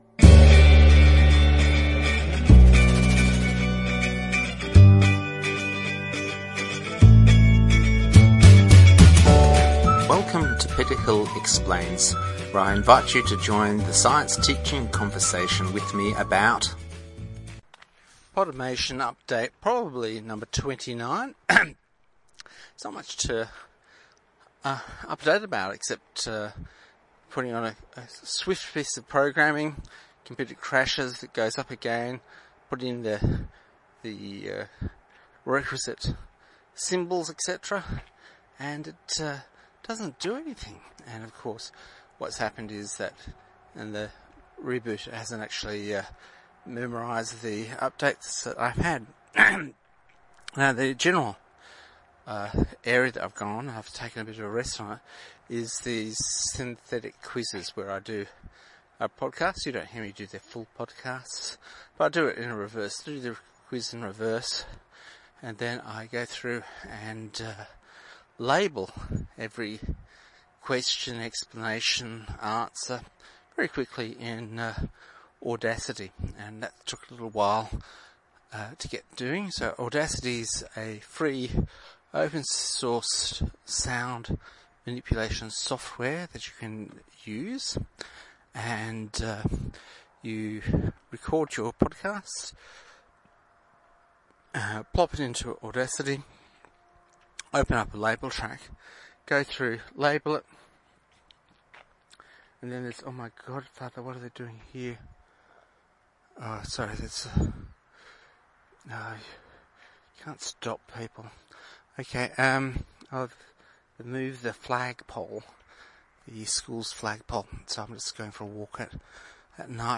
This is quiet up date where I experiment with the reverse order recording developed for synthetic lessons and use it for read-with-comment podcasts. In these podcasts I read a magazine until I get tired.
The idea is that after reading you record a summary and this is spliced to the front of the podcast describing whats in it.